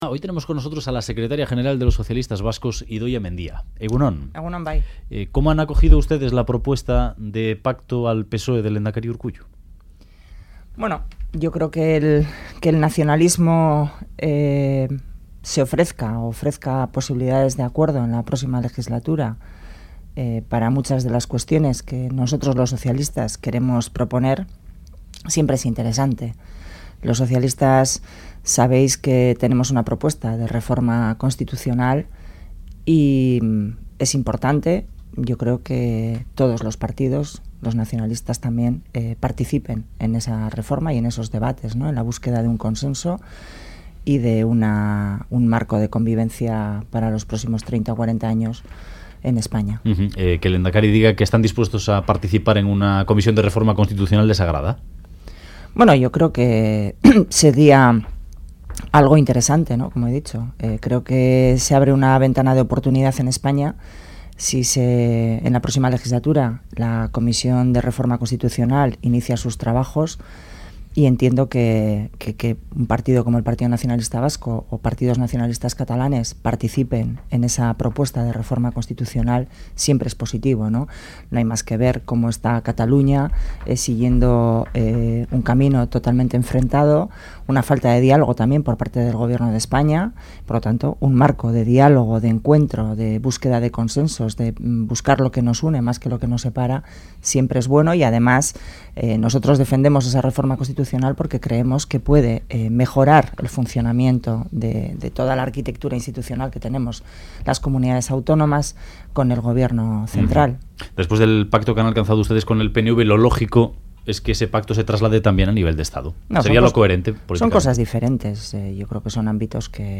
Entrevista a Idoia Mendia en Boulevard de Radio Euskadi